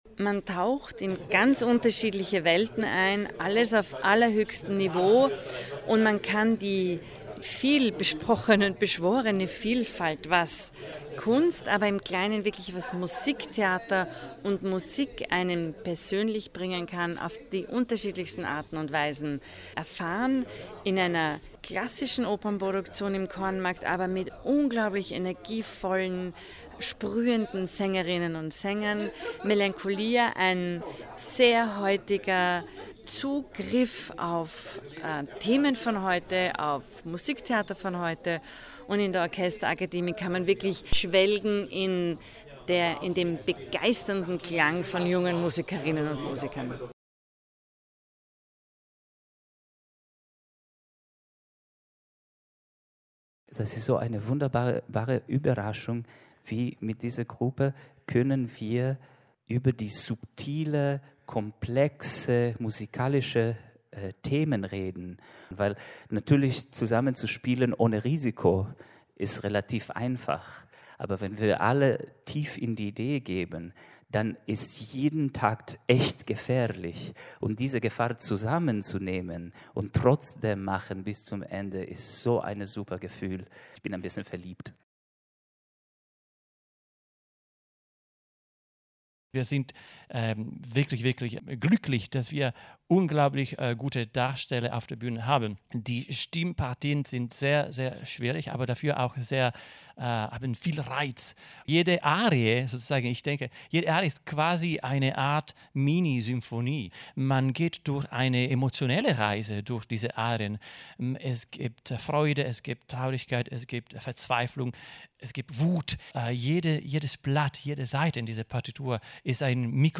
Pressetag II Audiobeitrag